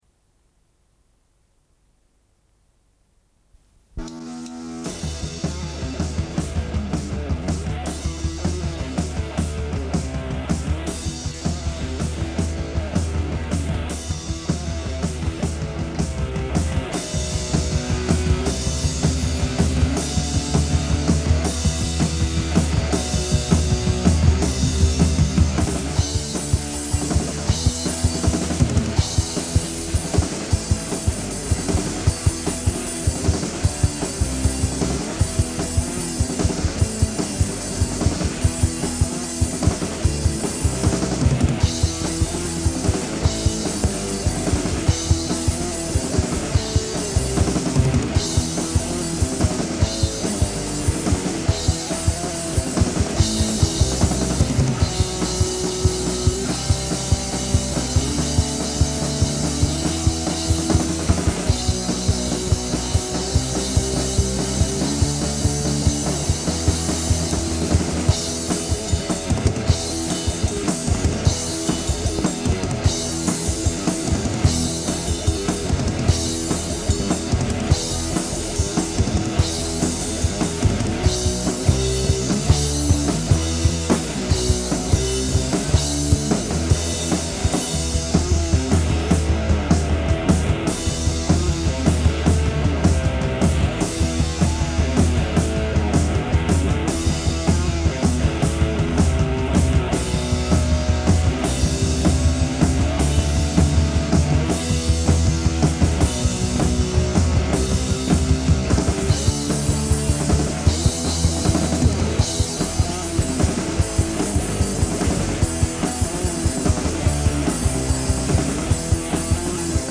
von der Tontechnik mitgeschnitten.